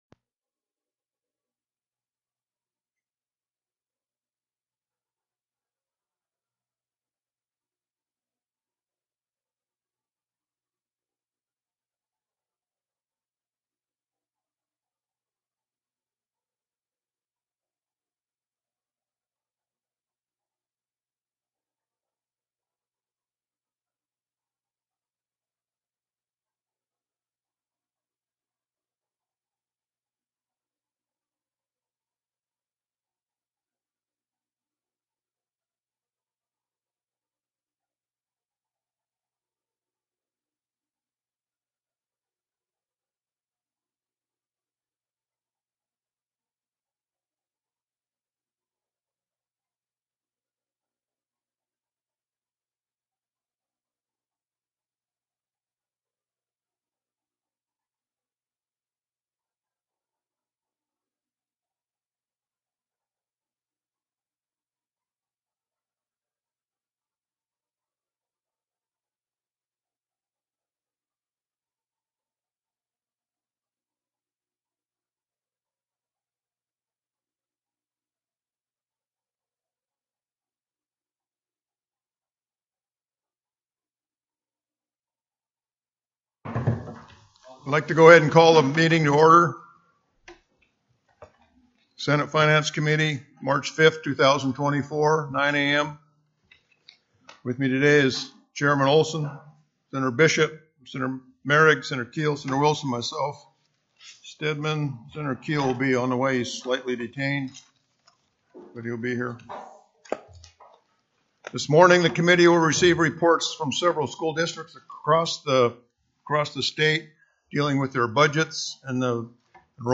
The audio recordings are captured by our records offices as the official record of the meeting and will have more accurate timestamps.
School District Fund Balance Discussion TELECONFERENCED